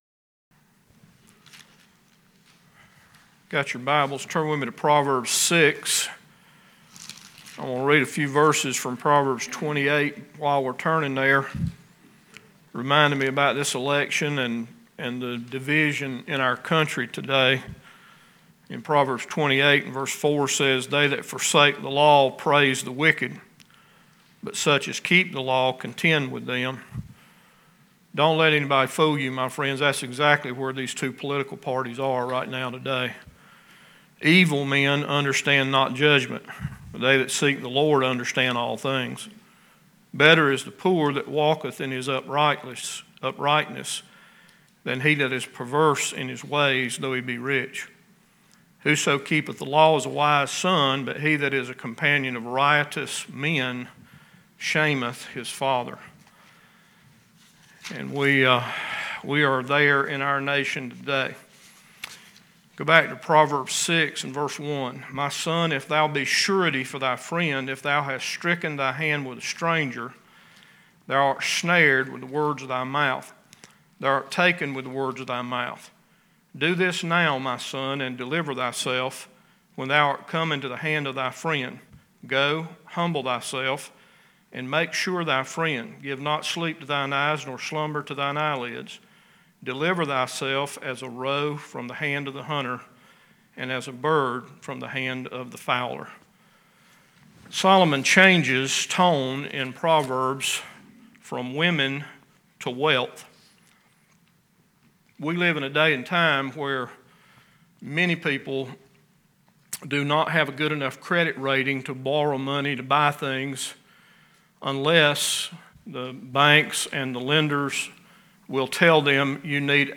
Wednesday evening study in Proverbs 6.